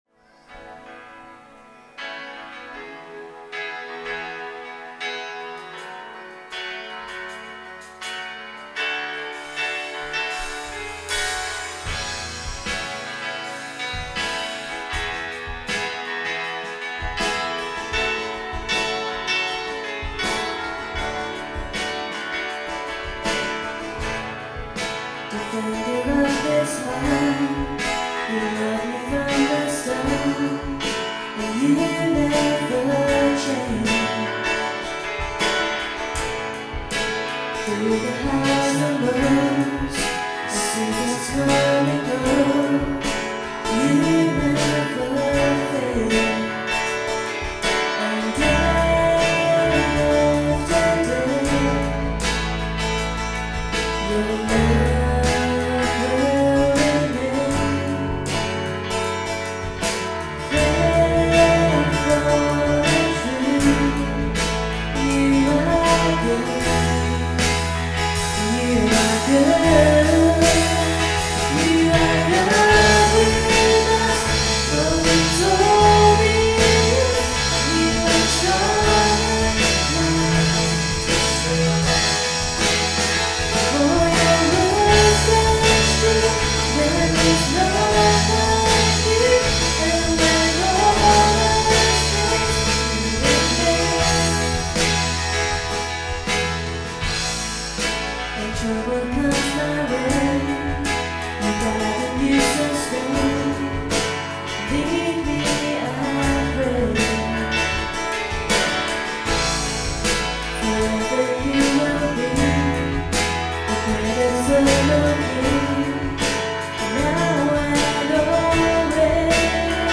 Worship February 22, 2015 – Birmingham Chinese Evangelical Church
Guitar
Vocals
Drums
Bass